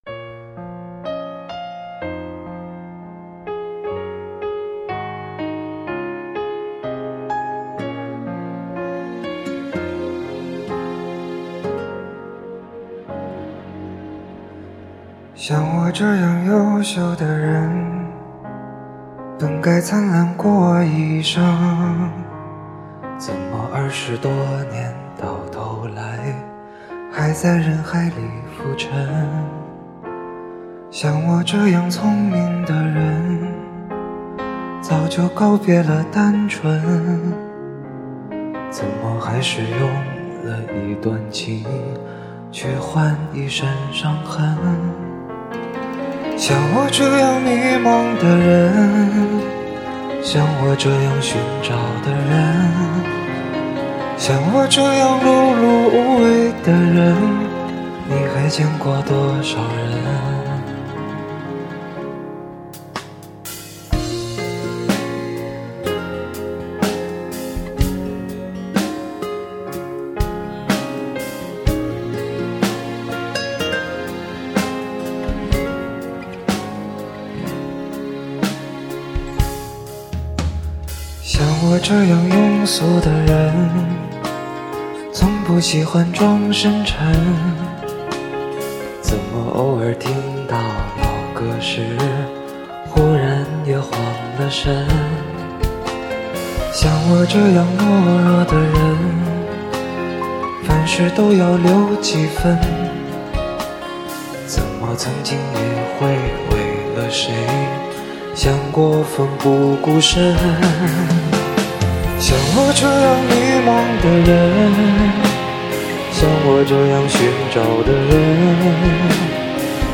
一张属于他的真正的现场收音，一次录制完成的个人首张专辑。
每首歌的高潮甚至连小字二组E都没有的平凡之声，用自嘲的口吻、诉说的声音跟你调侃自己，调侃这个社会。